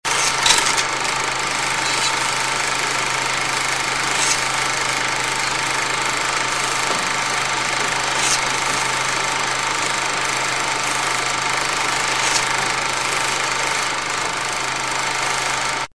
Proiettore pellicola film 16 mm
Rumore della meccanica del proiettore a pellicola per film da16 mm. Rumore della pellicola che striscia casualmente sul bordo della bobina.
proiettore16mm.mp3